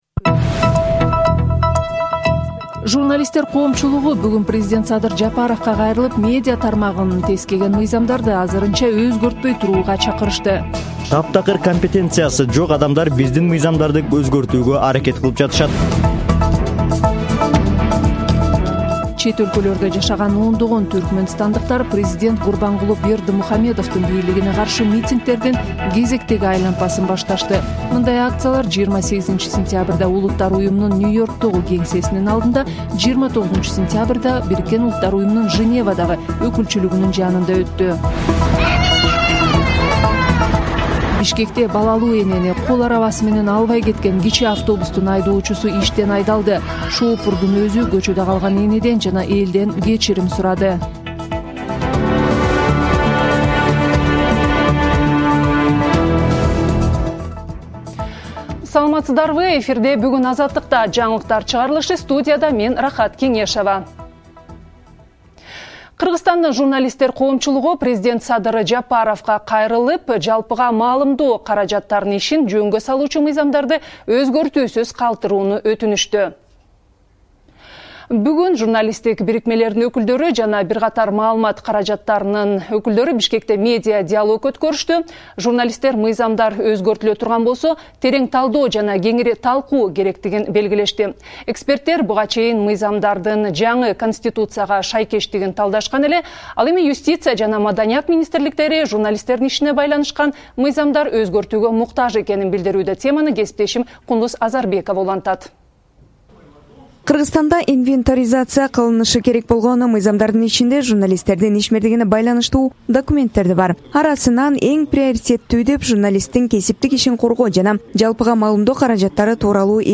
Жаңылыктар | 30.09.2021 | Журналисттер сөз эркиндигине кабатыр